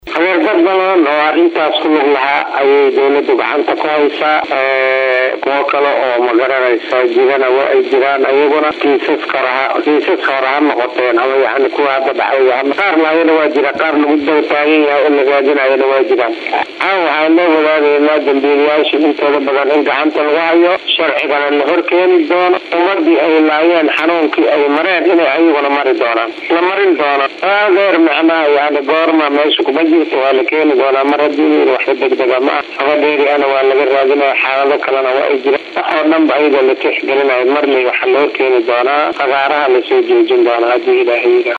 Mahad C/raxmaan Warsame, taliyaha ciidamada booliska ee gobolka Bay oo la hadlay Warbaahinta ayaa sheegay in baaritaano ciidamada ay sameeyeen lagu soo qabtay dad lagu tuhmayo iney ku lug lahaayeen qaraxyadii ka dhacay Baydhabo.